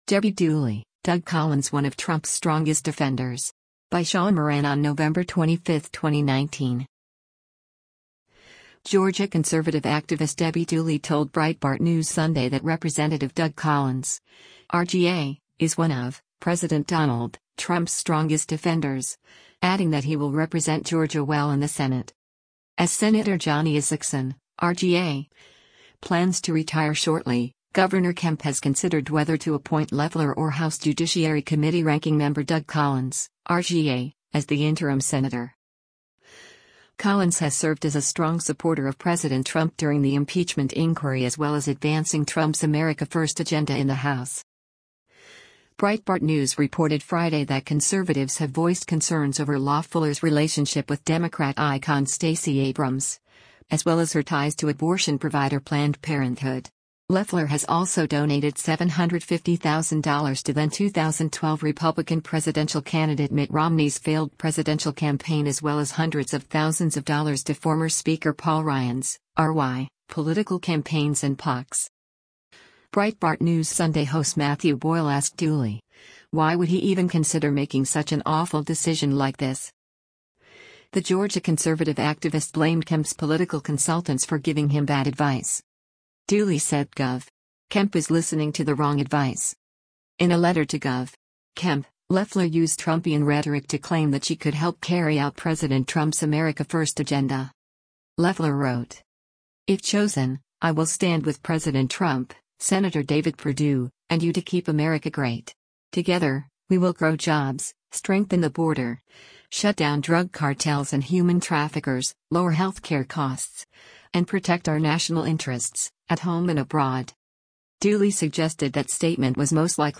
Breitbart News Sunday airs on SiriusXM Patriot 125 from 7:00 P.M. to 10:00 P.M. Eastern.